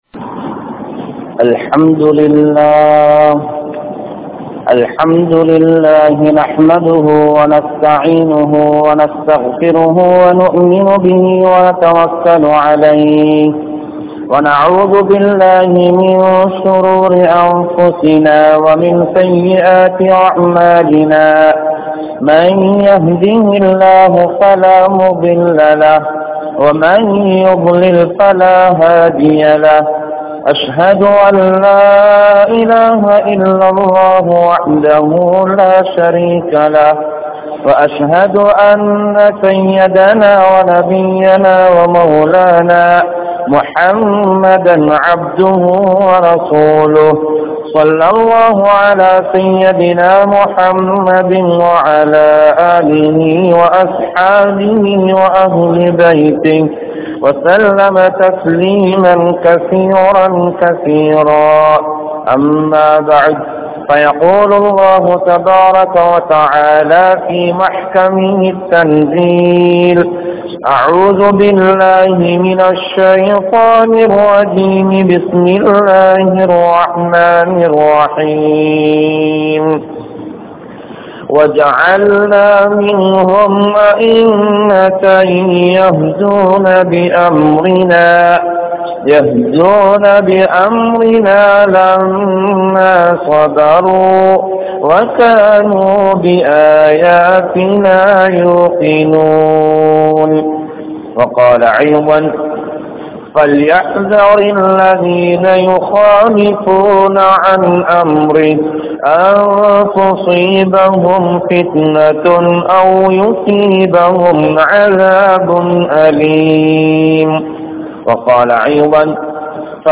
Soathanaihalum Manitharhalum (சோதனைகளும் மனிதர்களும்) | Audio Bayans | All Ceylon Muslim Youth Community | Addalaichenai